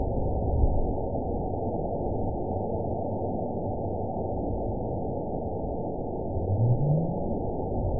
event 916924 date 02/18/23 time 22:13:13 GMT (2 years, 2 months ago) score 9.69 location TSS-AB01 detected by nrw target species NRW annotations +NRW Spectrogram: Frequency (kHz) vs. Time (s) audio not available .wav